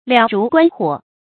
燎如观火 liǎo rú guān huǒ
燎如观火发音